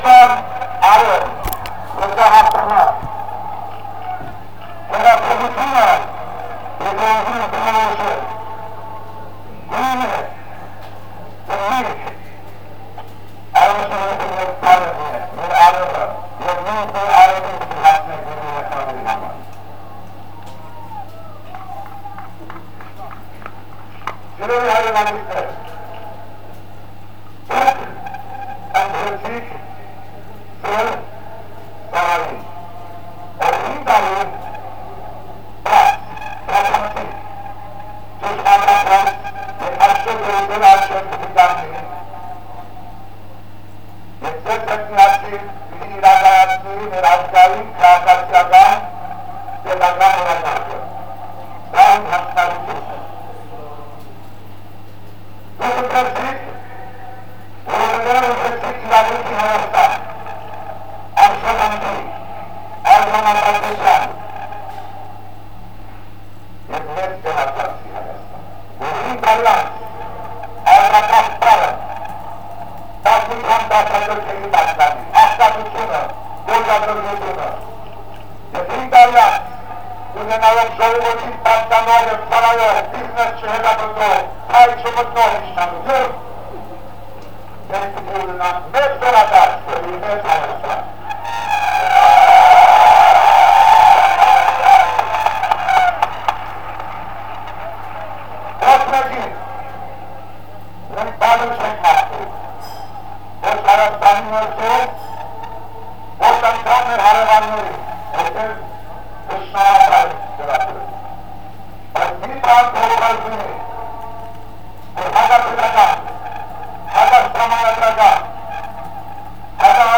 Րաֆֆի Հովհաննիսյանի ելույթը